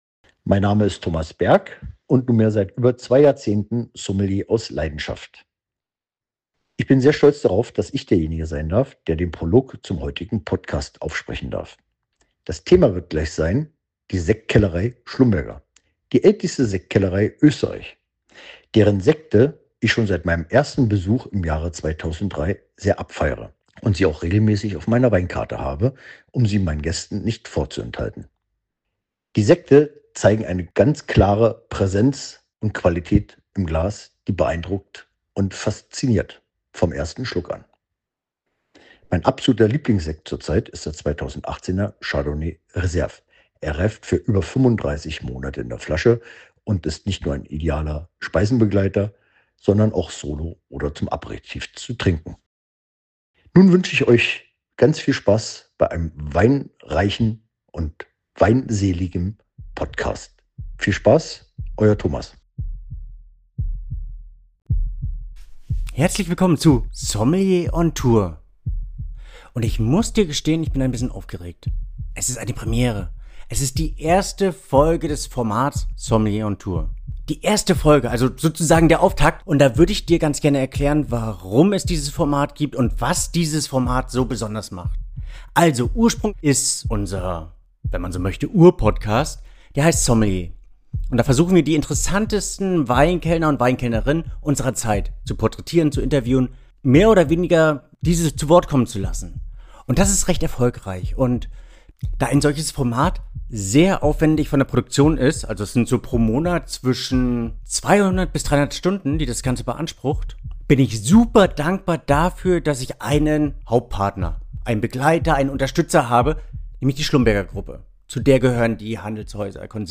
Ein seltenes, intensives Gespräch, das zeigt: Schlumberger-Sekt ist ein Erlebnis, das Kultur, Emotion und Zeit verdichtet, um die Seele des Sekts zu begreifen.